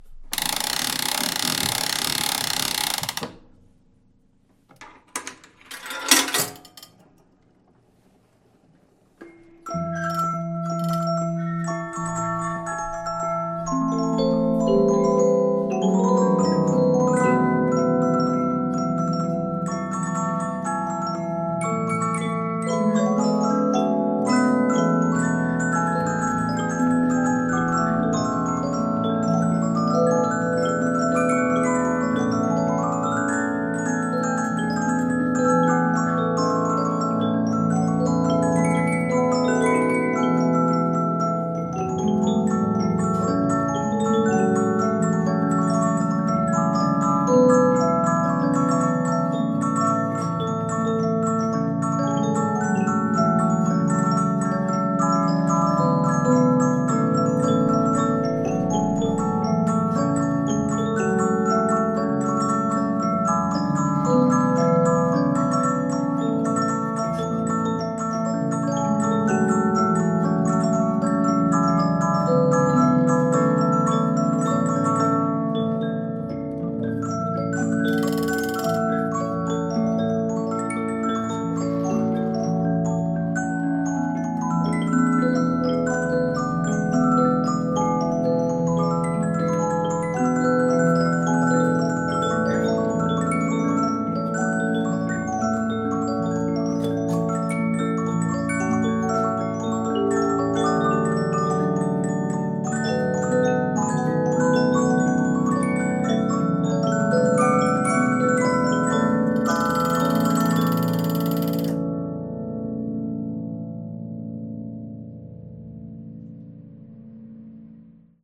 Boîte à musique à disque métallique murale ou de table à monnayeur, fabriqué par Polyphon.
Le disque est cranté et vient relever une roue à étoile. Celle-ci vient relever les lamelles du clavier métallique.